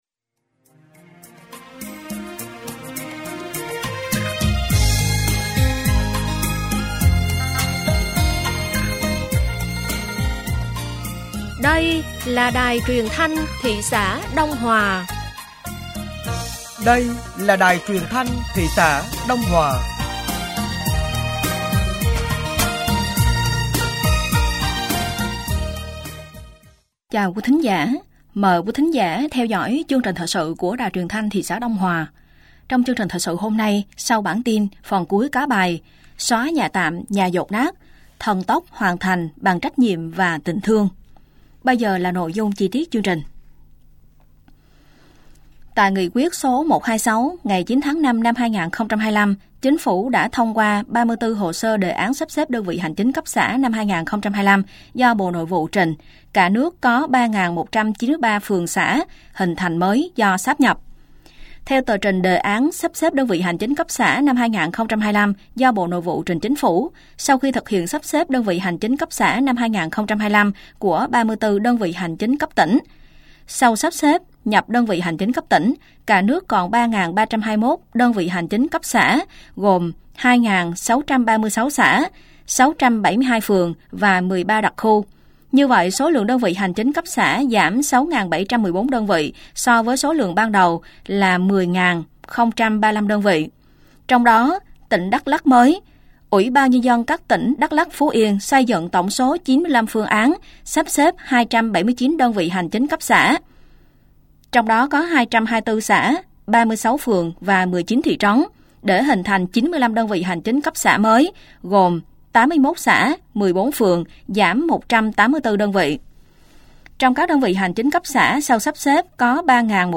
Thời sự tối ngày 12 và sáng ngày 13 tháng 5 năm 2025